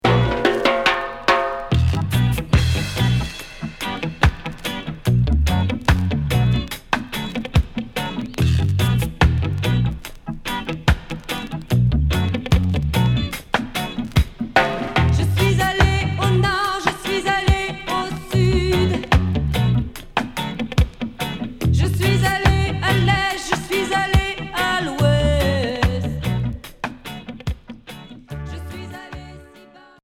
Variété rock et reggae Onzième 45t retour à l'accueil